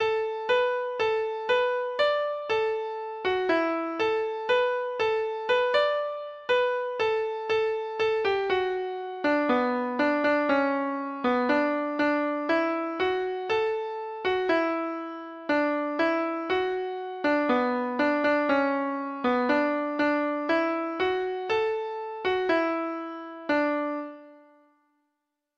Folk Songs from 'Digital Tradition' Letter T The Banks o' Skene
Treble Clef Instrument  (View more Intermediate Treble Clef Instrument Music)
Traditional (View more Traditional Treble Clef Instrument Music)